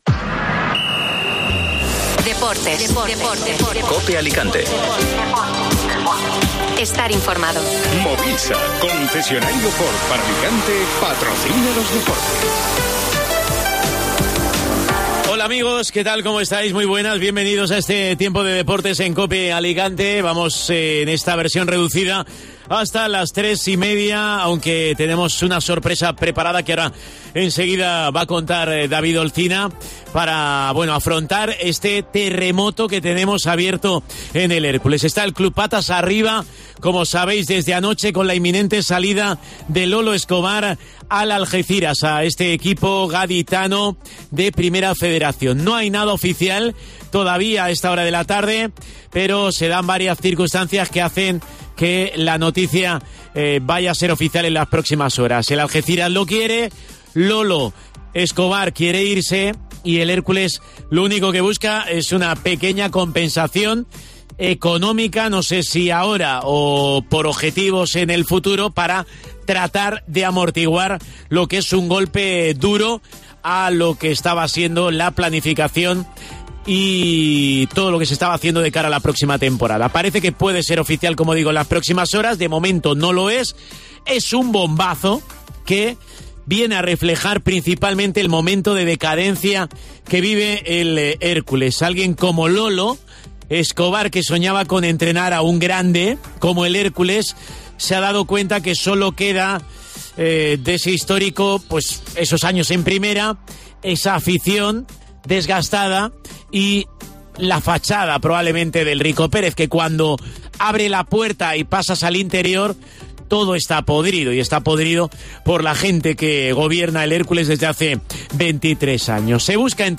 en una sesión de 'Facebook Live'